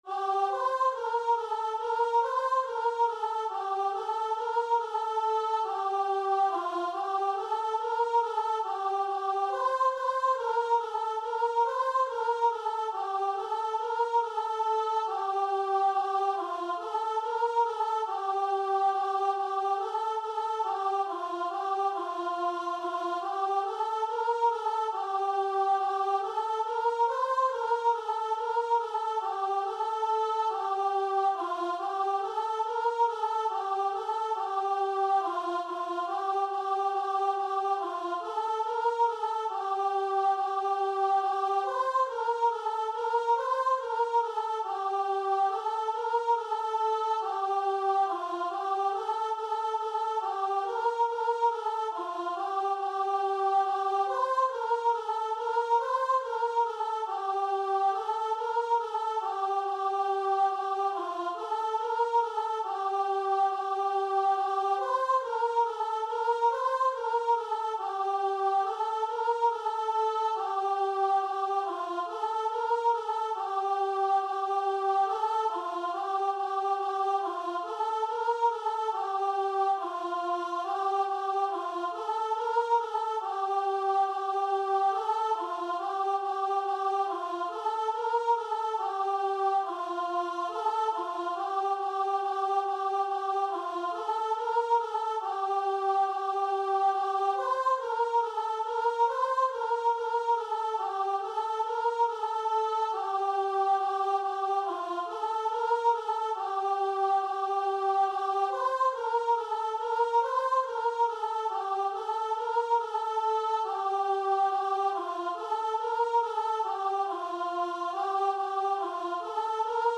Free Sheet music for Choir
G minor (Sounding Pitch) (View more G minor Music for Choir )
4/4 (View more 4/4 Music)
Christian (View more Christian Choir Music)